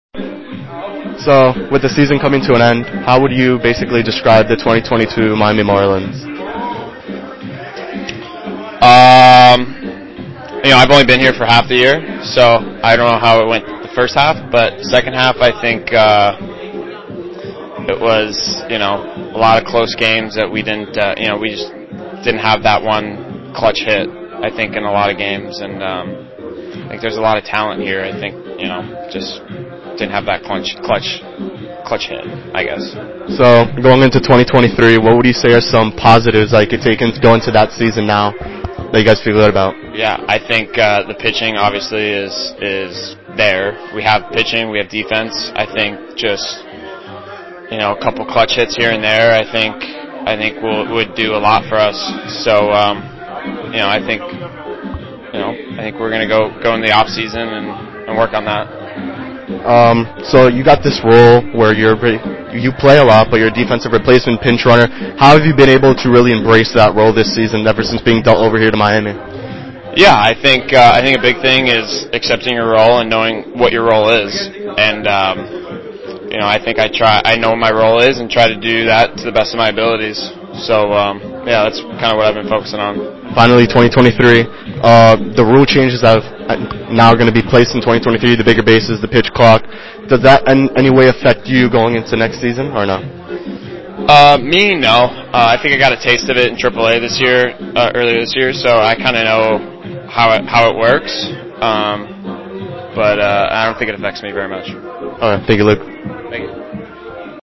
here is a conversation that I had with Williams about the 2022 season and what he’s looking forward to in the future.
Luke_Williams_pregame_2.mp3